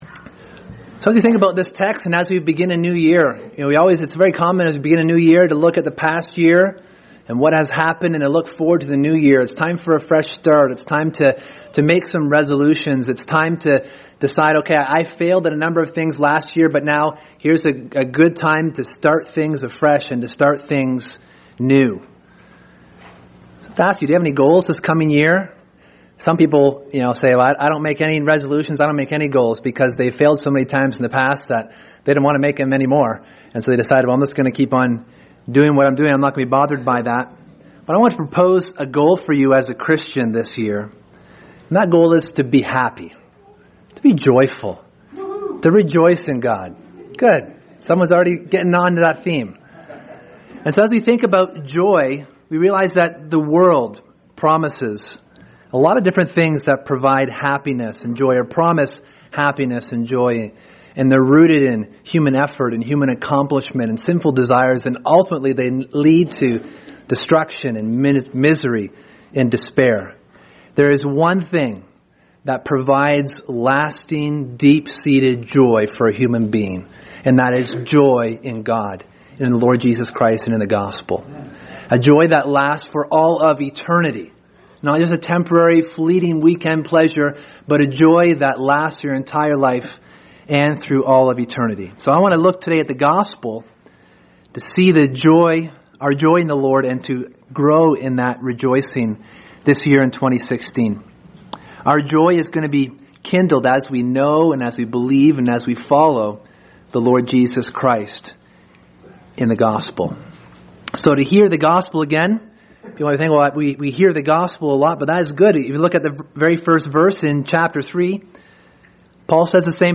Sermon: The Surpassing Worth of Knowing Christ